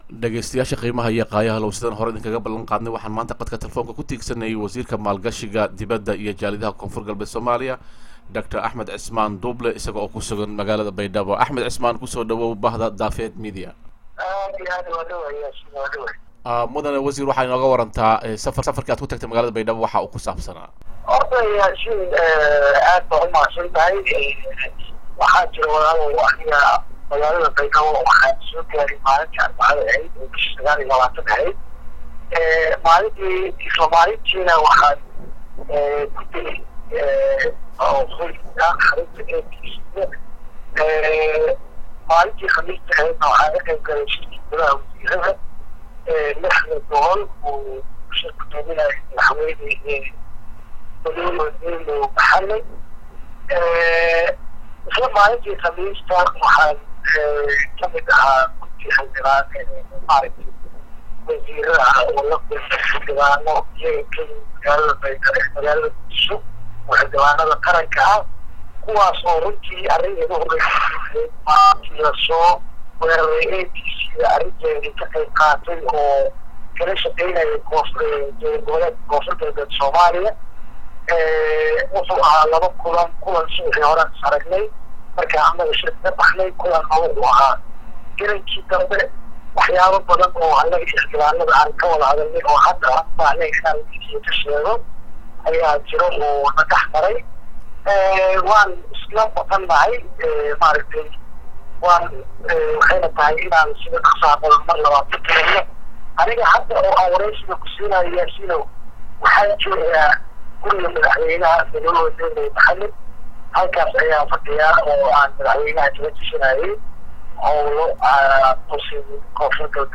Marka si aan wax uga ogaano in arintaas ay sax tahay ayaan waxaan qadka telfoonka ku tiigsaney Wasiirka maalgashiga dibada iyo jaaliyadaha ee koonfur galbeed somaliya Dr Axmed Osman Duuble    isaga oo ku sugan magalada baydhaba wareysigiina waxaa uu u dhacay sidan.